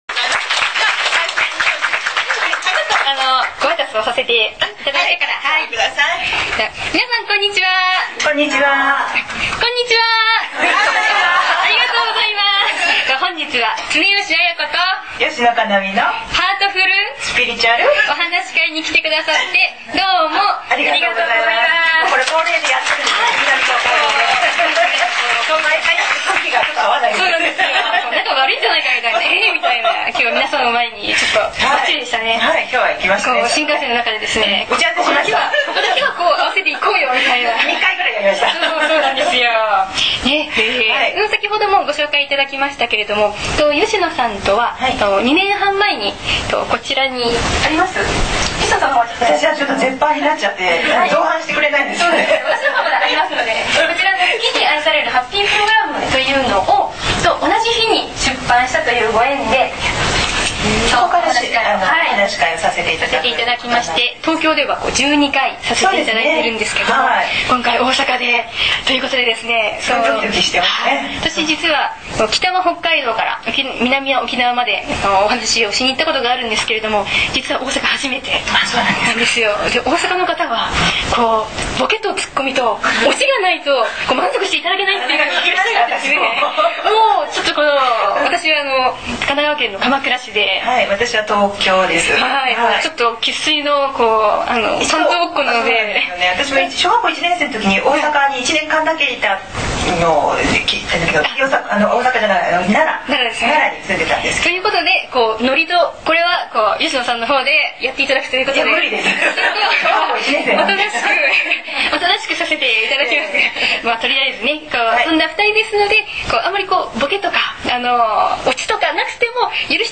ハートフル・スピリチュアルお話会in大阪 CD
Q＆Ａ
音声全体にノイズがございます。
収録時のノイズですのでＣＤの不良品ではございませんことをご了承ください。
合計収録時間　１１３分４３秒　クレオ大阪中央にて収録